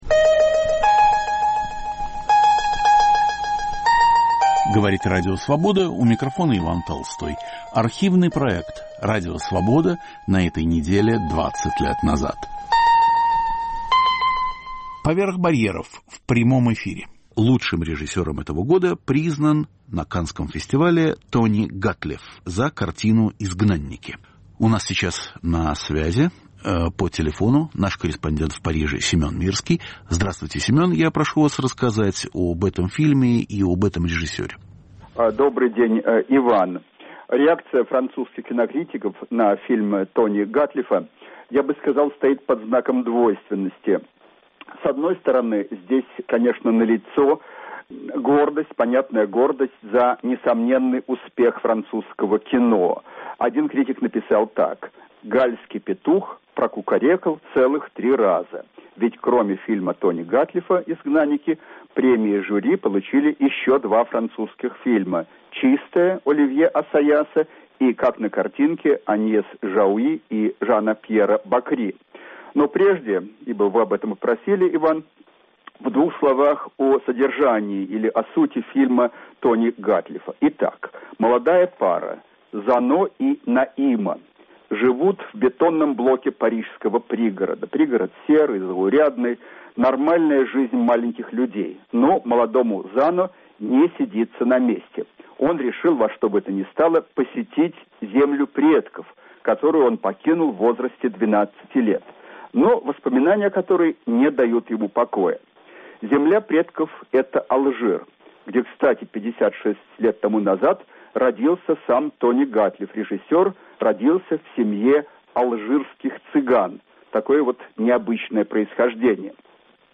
О лучшем режиссере 2004 года и его фильме в жанре роуд-муви. Итоги Каннского фестиваля. В программе участвуют кинокритики и корреспонденты из Парижа, Нью-Йорка и Москвы.